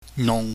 Cantonese Sounds-Mandarin Sounds
nung nong
nungLF.mp3